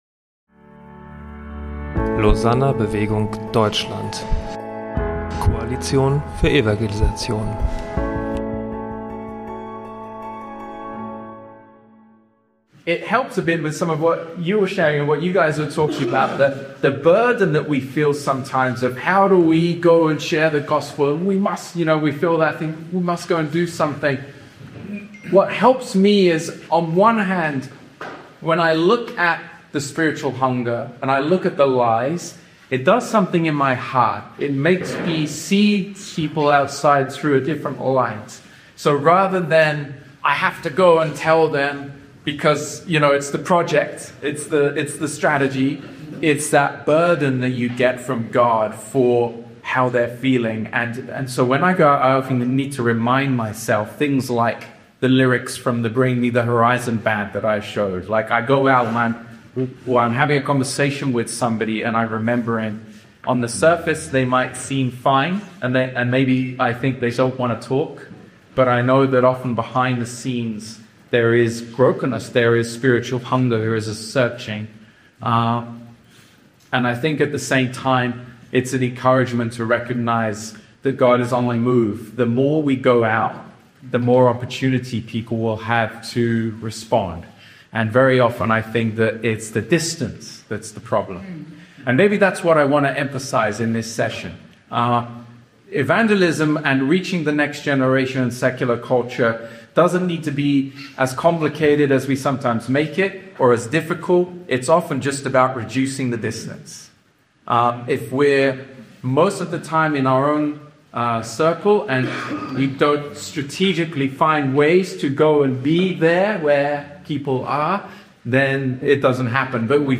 Beim Lausanner Forum am 19. Januar 2026 in Marburg